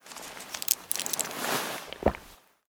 pills_use.ogg